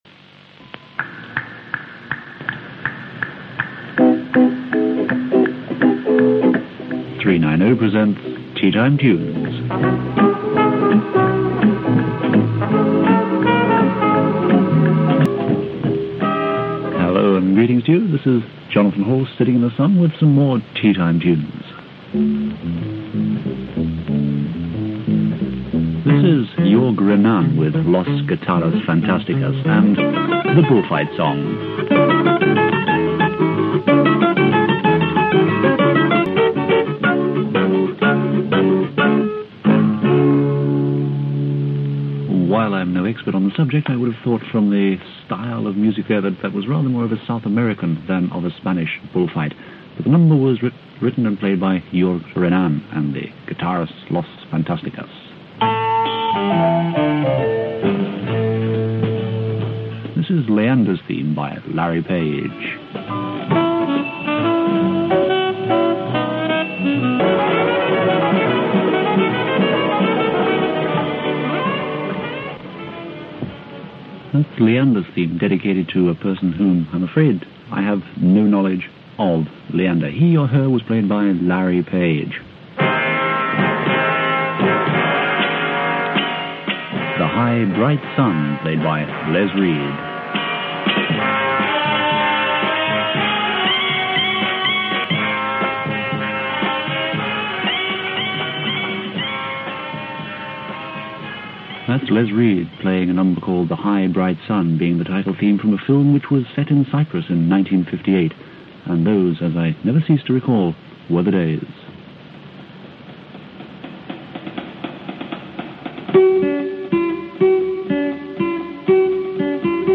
The unique selling point of Radio 390 was its substantially different format - ‘sweet music’ (what     today may be termed ‘smooth’ or ‘easy listening’).